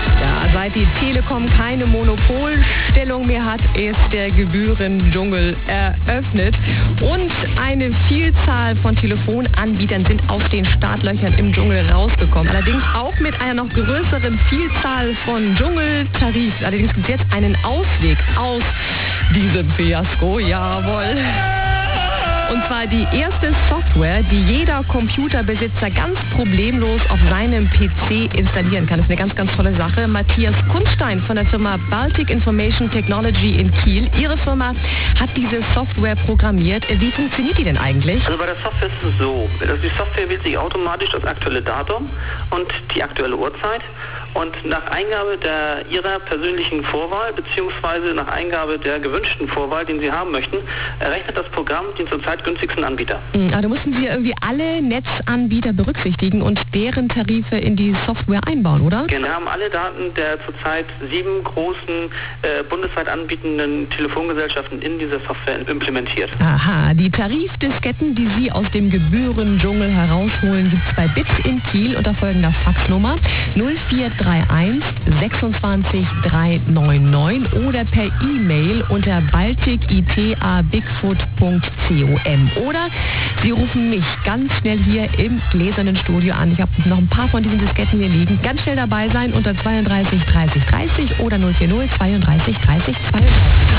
bitTel Telefonieren & Sparen (Radiomitschnitte)
Interview und Verlosung vom 29. Januar 1998